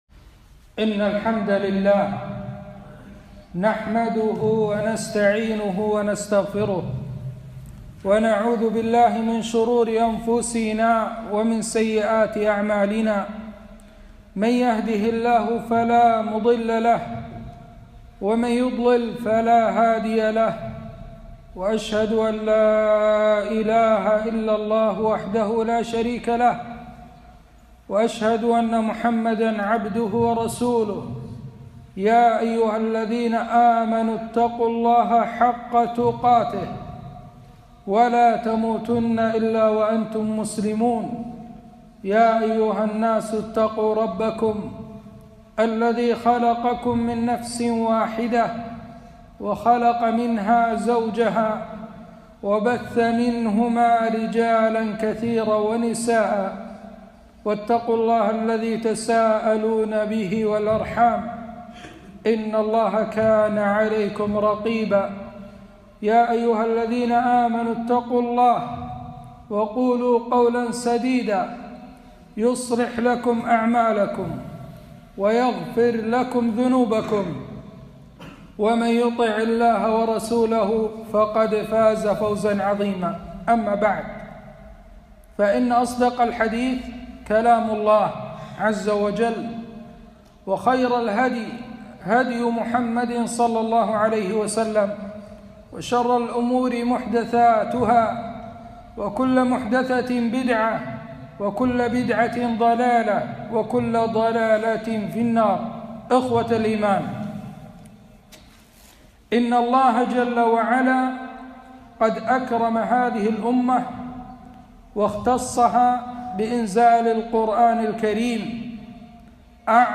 خطبة - وننزل من القرآن ماهو شفاء